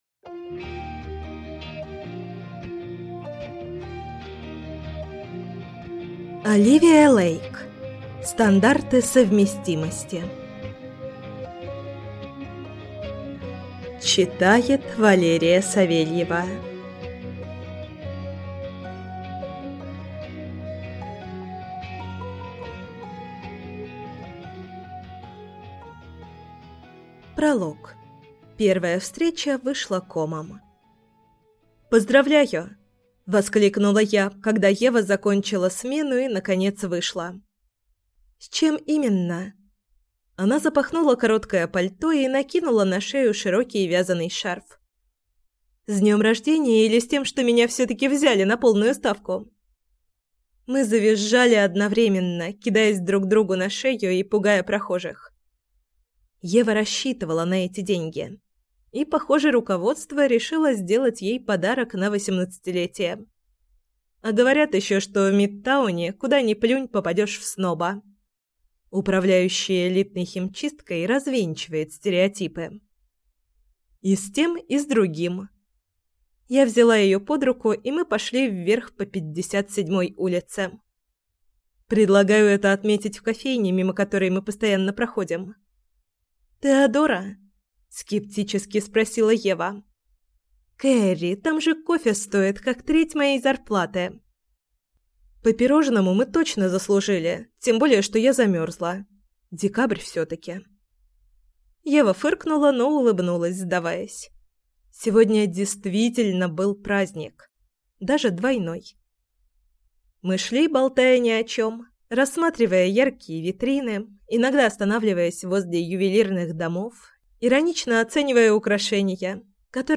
Аудиокнига Стандарты совместимости | Библиотека аудиокниг